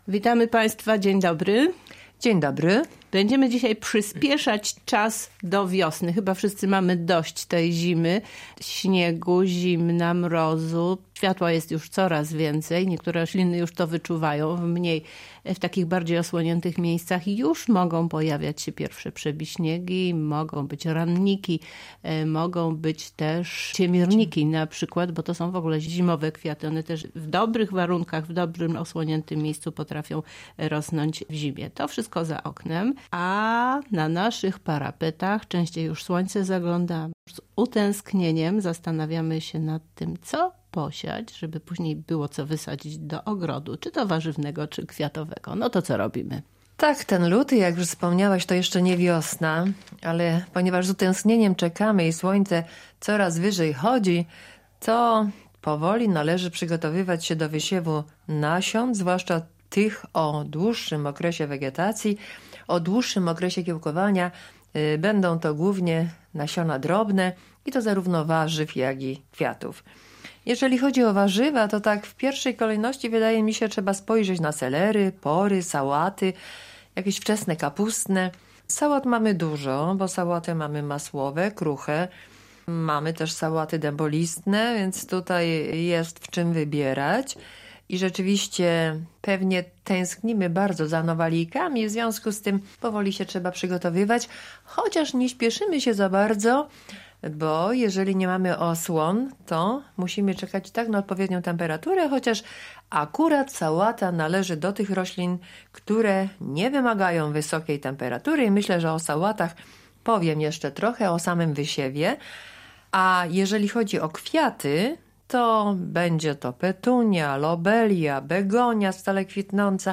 rozmowie